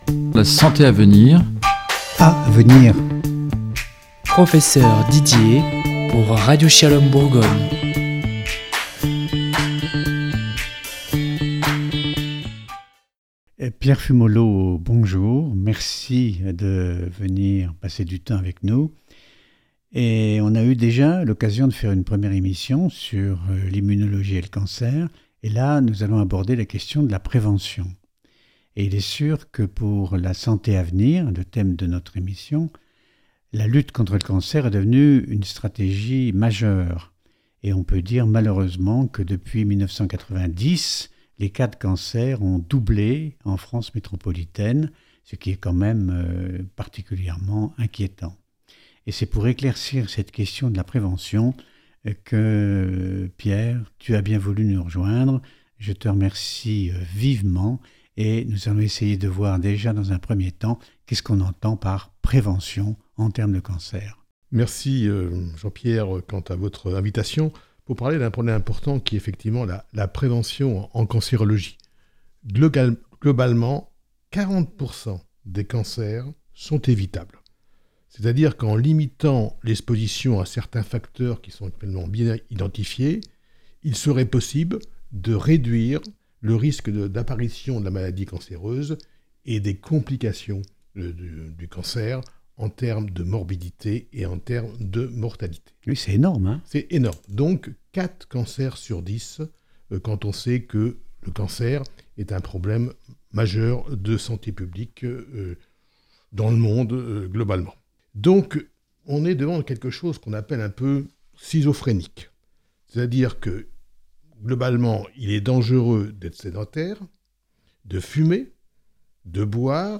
Cliquez-ici pour écouter l'émission précédente Interview